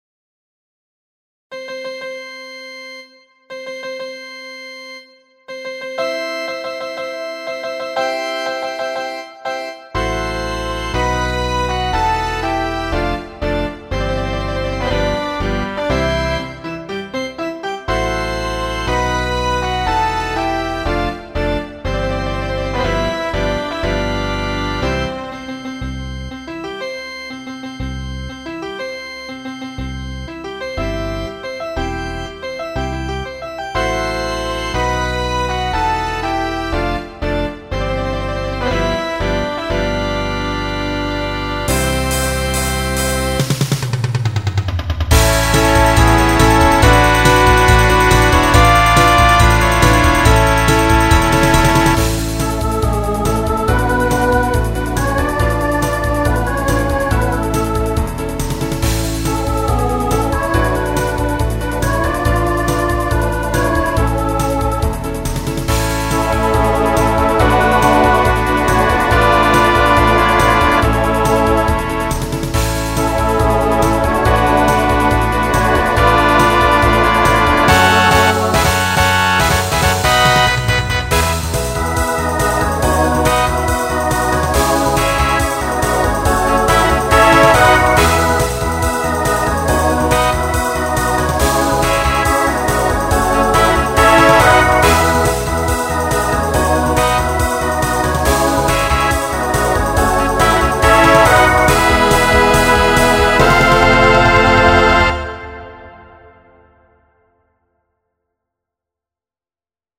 Genre Pop/Dance Instrumental combo
Transition Voicing SATB